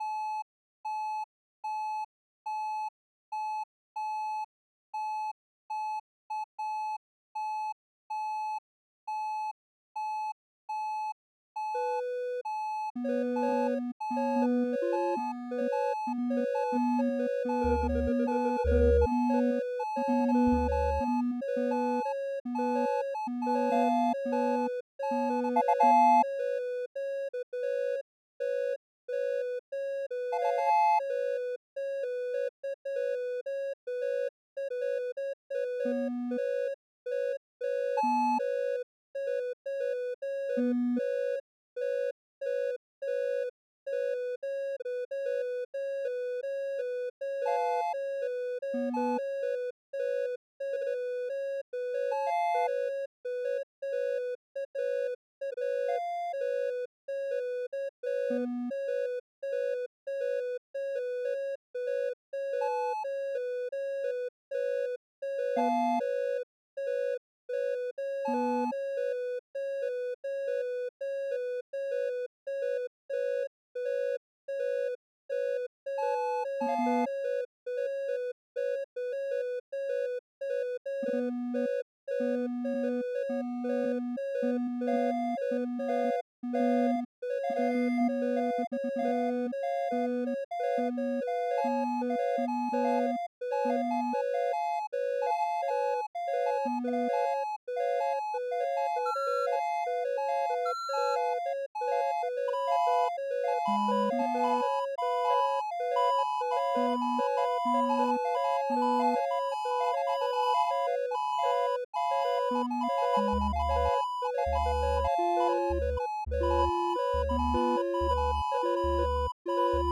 Basically, every "note" or piece of sound represents one change in the Linux kernel between v5.16 and v5.17. There are 14,200 of them, and the audio track lasts 4 minutes so wait for the big fireworks around the end.